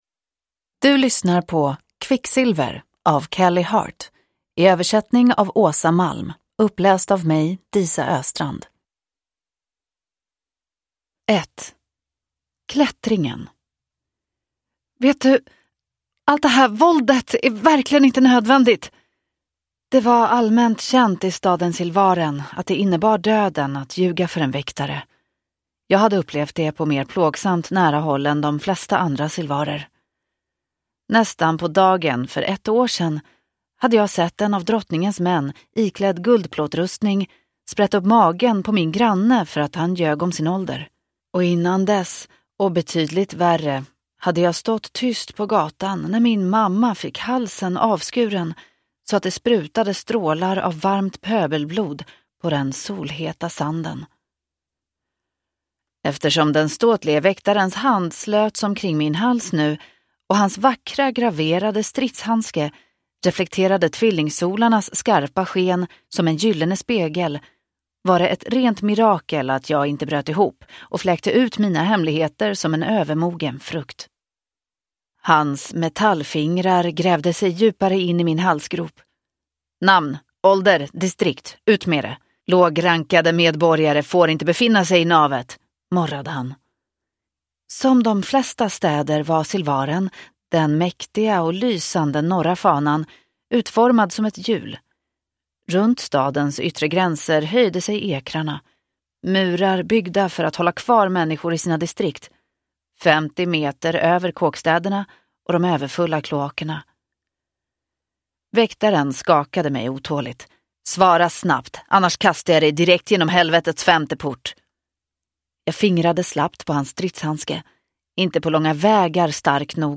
Quicksilver (svensk utgåva) – Ljudbok